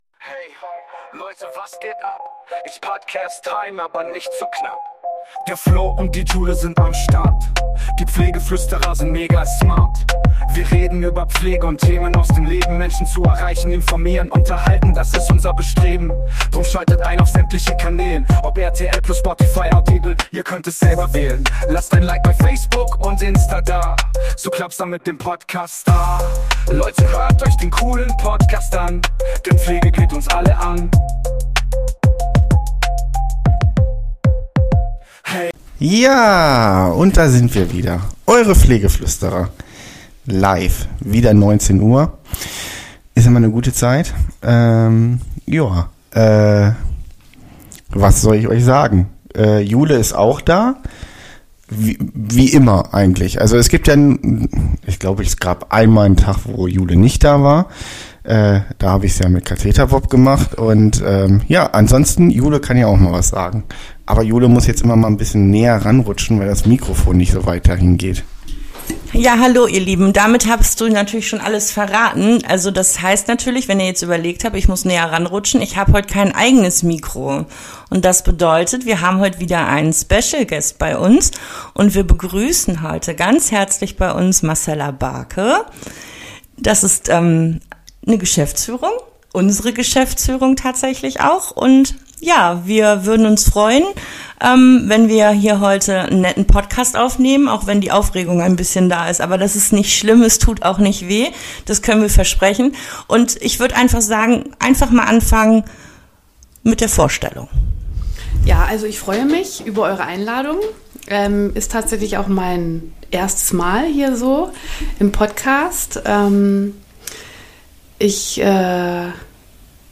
Heute mal eine andere Art von Frage/Antwort spiel.